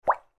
دانلود آهنگ آب 44 از افکت صوتی طبیعت و محیط
دانلود صدای آب 44 از ساعد نیوز با لینک مستقیم و کیفیت بالا
جلوه های صوتی